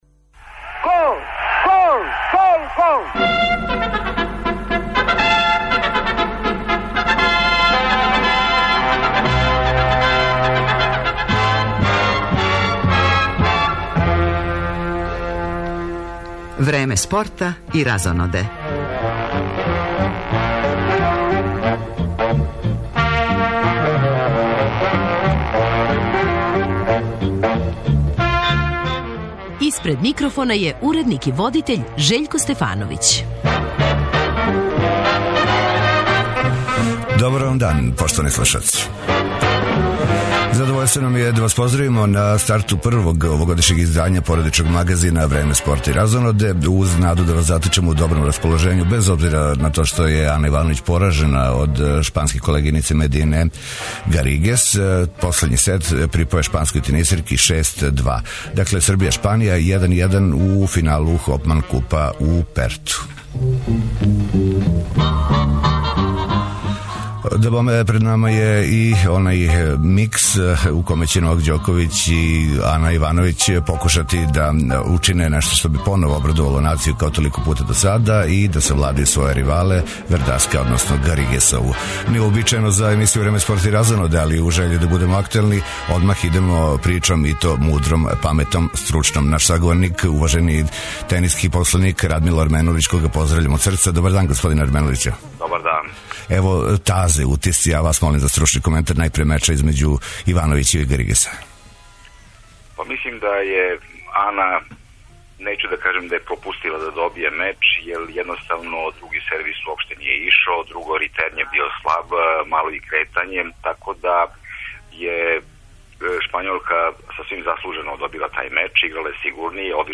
У студију ће нам се придружити и браћа Теофиловић. Најавићемо њихов скорашњи концерт у Коларчевој задужбини, а овај сусрет сигурно неће проћи без њиховог певања уживо, за шта су прави специјалисти.